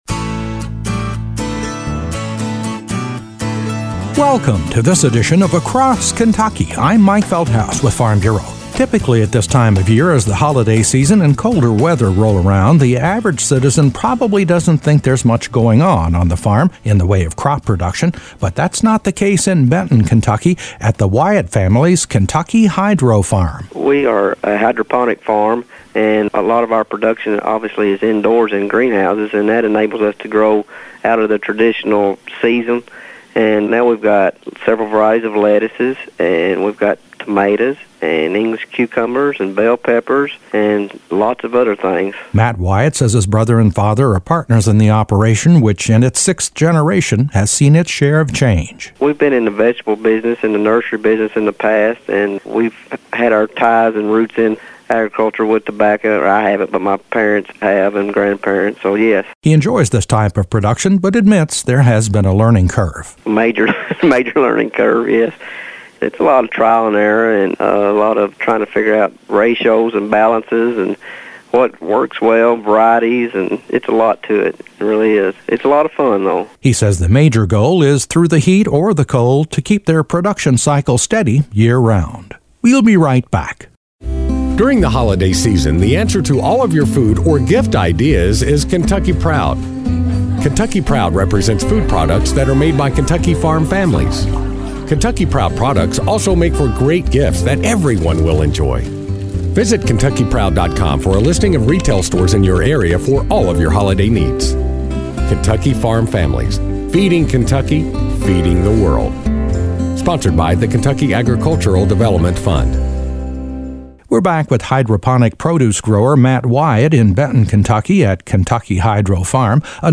A report on a 6th generation farming operation in Benton, Ky that’s evolved out of tobacco and into hydroponic production.